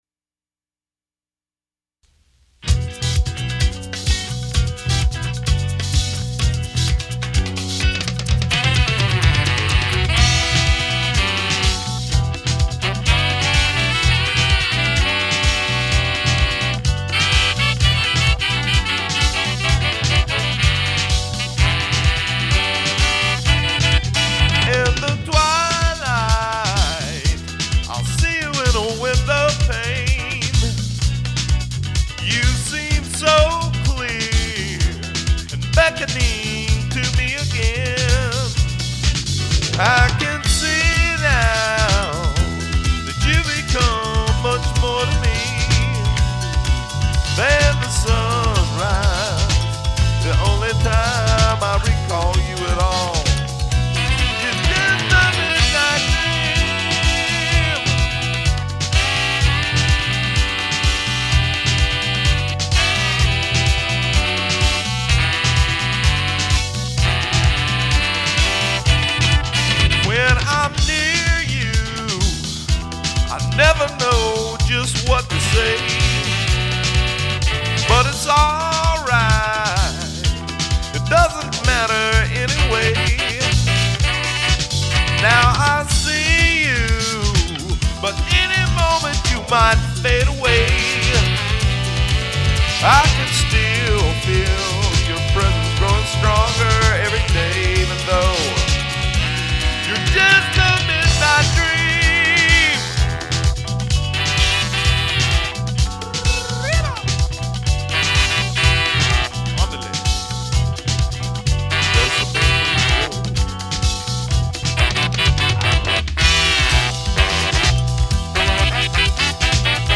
All done on a single 3340 4-track reel to reel and all pings are done within the one machine and then finally mixed to cassette. I think this is probably 6th generation ( a concept that disappeared with digital) so I'm kinda proud of how semi-quiet it is. There are 4 horns/2 guitars/bass/keyboards/vox/drumbs. Drums are done with a Drum Drop record (Vinyl records with drum tracks .... before decent sounding drum machines). I'm playing everything so it's one track at a time. Attachments midnite dream.mp3 midnite dream.mp3 7.8 MB · Views: 67